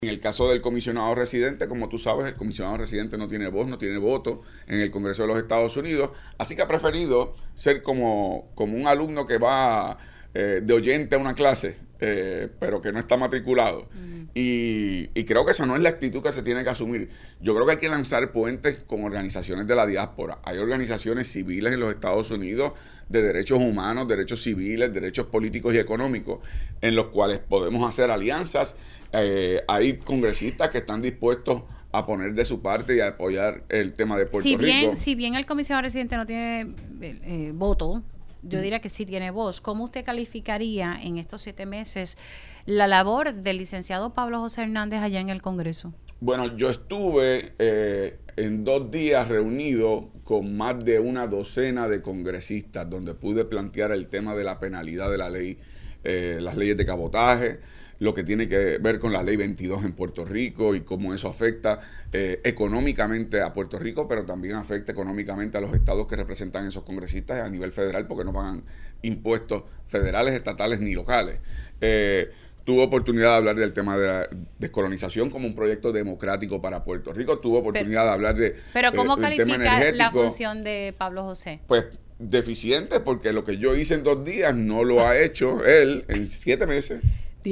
Hay congresistas que están dispuestos a poner de su parte y a apoyar el tema de Puerto Rico“, destacó Dalmau Ramírez en Pega’os en la Mañana.